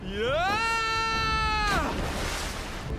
Play, download and share Whoaa! original sound button!!!!
whoaaaaa-ffxiv.mp3